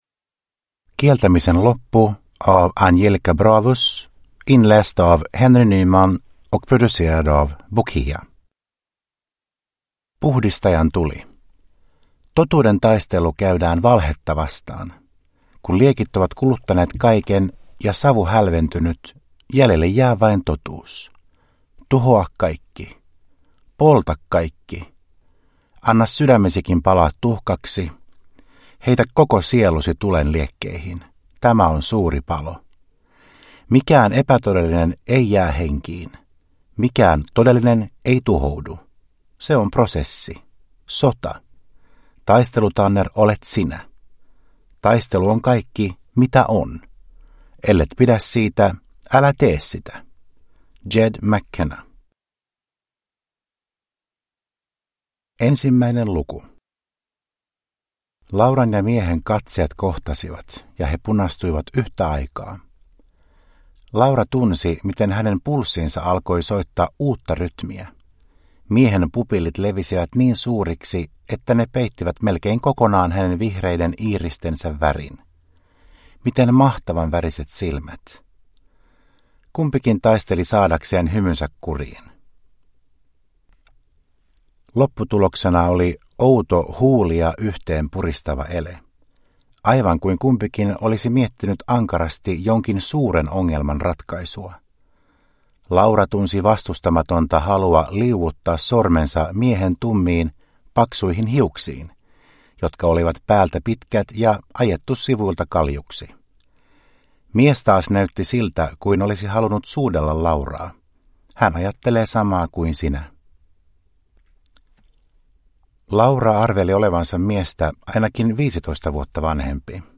Kieltämisen loppu – Ljudbok